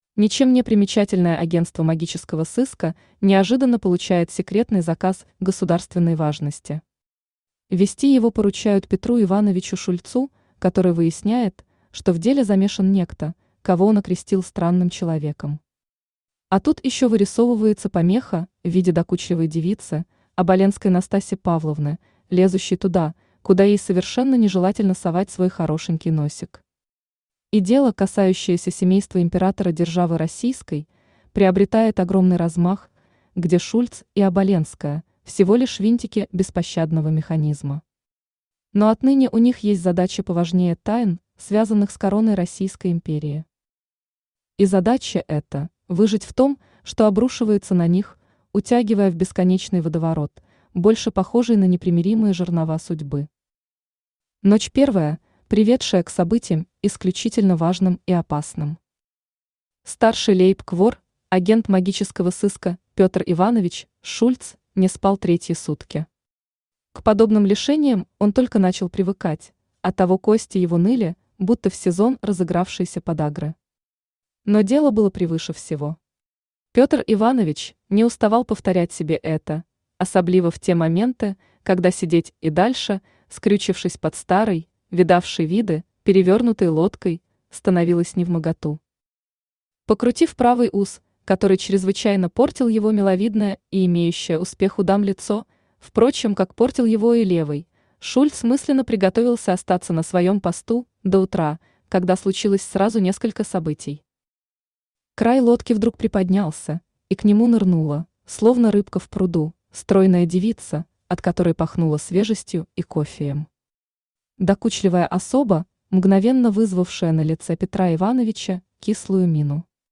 Aудиокнига БАМС! Безымянное агентство магического сыска Автор Тати Блэк Читает аудиокнигу Авточтец ЛитРес.